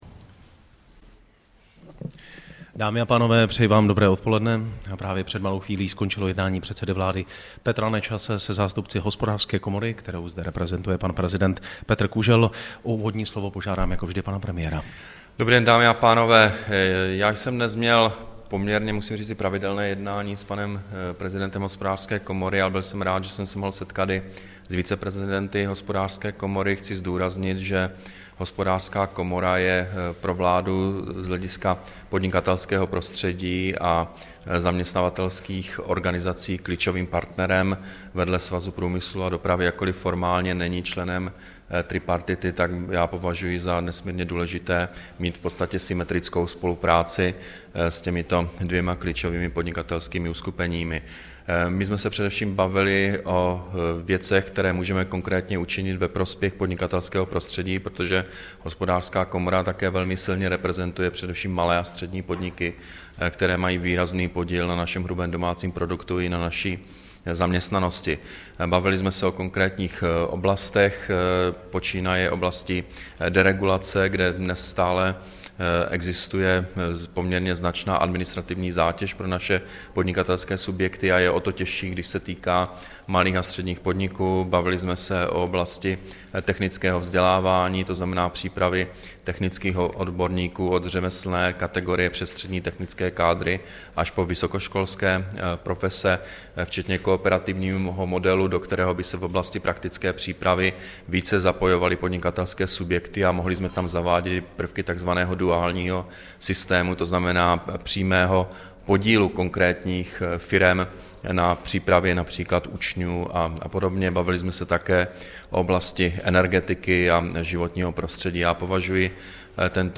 Tiskový brífink po jednání premiéra se zástupci Hospodářské komory ČR, 9. května 2013